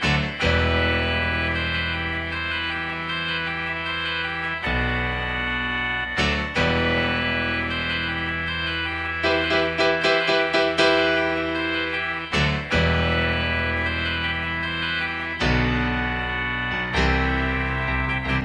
Loop 1_Get It Together.wav